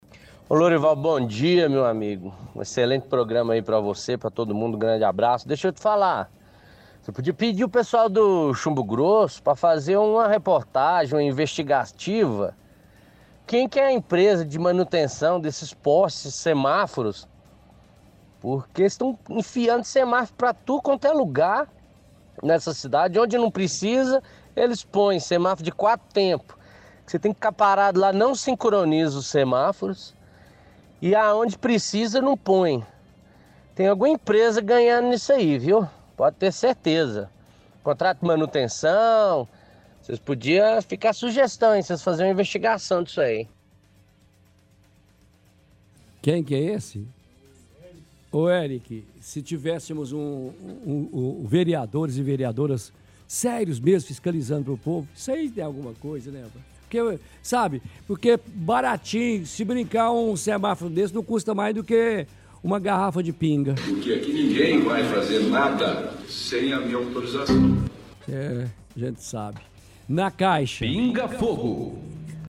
– Ouvinte reclama da quantidade de semáforos na cidade.